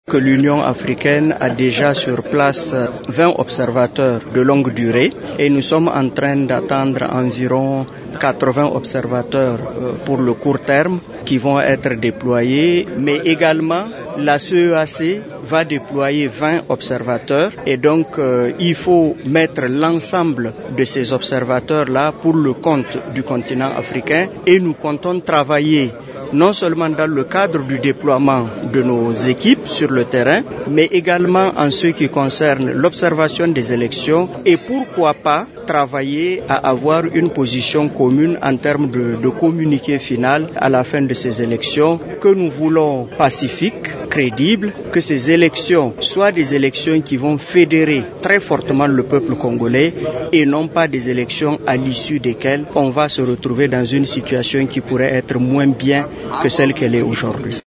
L’ambassadeur Abdou Abarry, représentant de l’Union africaine en RDC a affirmé que l’Union Africaine va aussi assurer l’observation des élections du 23 décembre prochain avec plus de 100 observateurs. Il estime que ces élections doivent fédérer le peuple congolais.